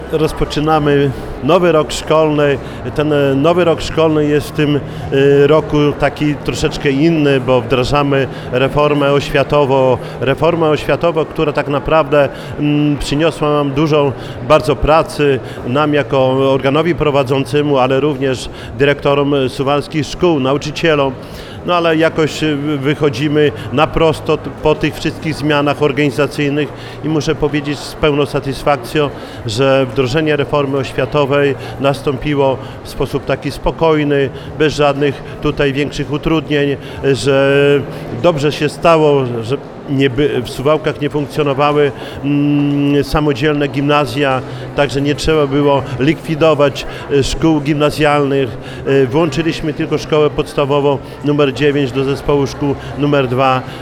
Jak wiadomo w tym roku rusza reforma oświatowa, która ma wygasić gimnazja i przywrócić ośmioletnie szkoły podstawowe, stąd w tym roku klasy siódme. O zmianach związanych z reformą mówił Czesław Renkiewicz prezydent miasta.